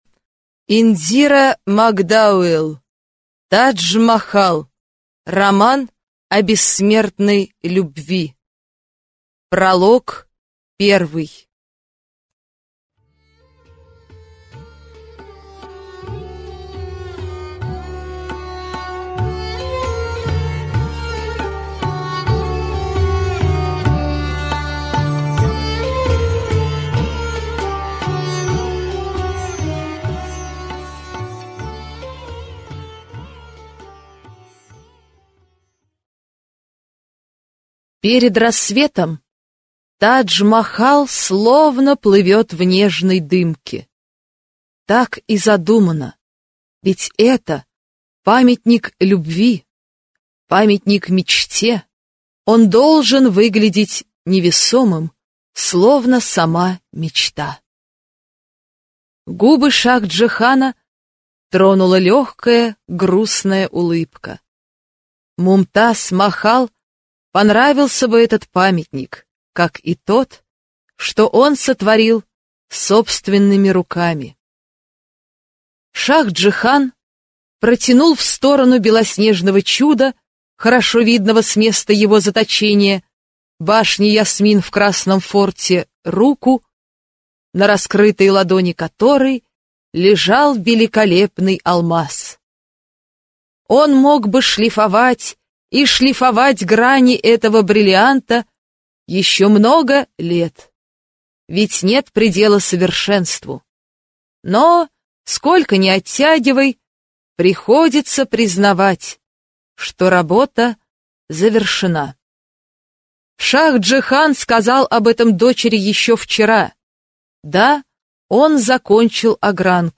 Аудиокнига Тадж-Махал. Роман о бессмертной любви | Библиотека аудиокниг